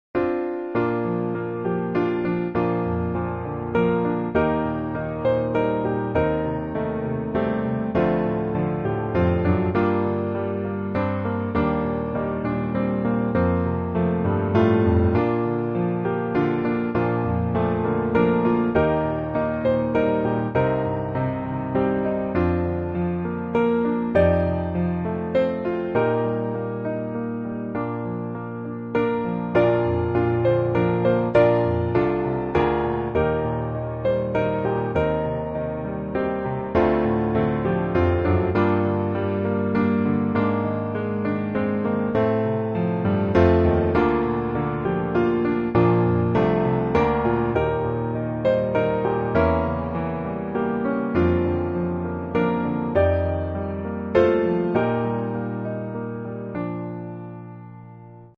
降B大调